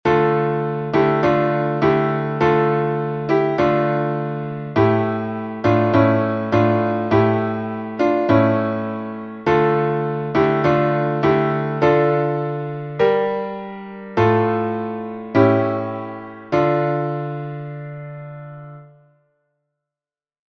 только аккомпанемент